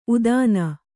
♪ udāna